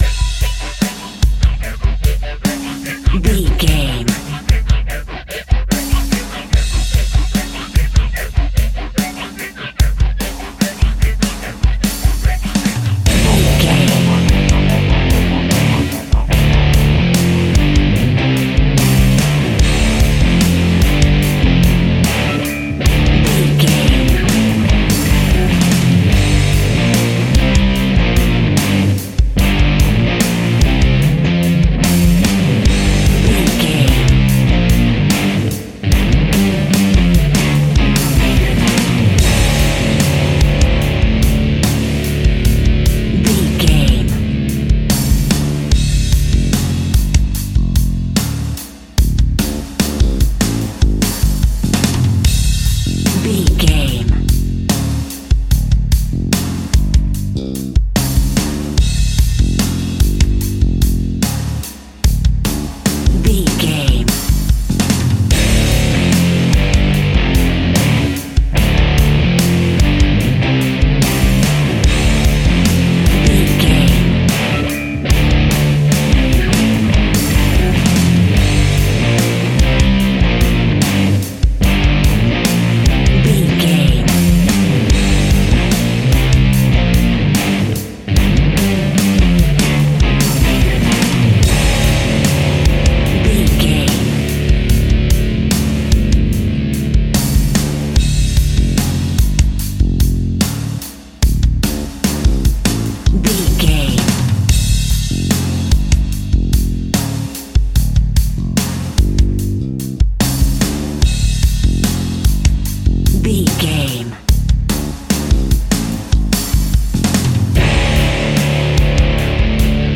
Powerful Rock Music Cue Alt Mix.
Fast paced
Aeolian/Minor
heavy metal
distortion
rock guitars
Rock Bass
Rock Drums
heavy drums
distorted guitars
hammond organ